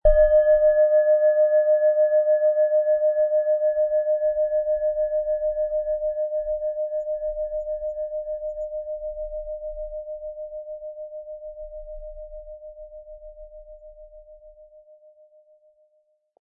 Diese tibetische Planetenschale Eros ist von Hand gearbeitet.
MaterialBronze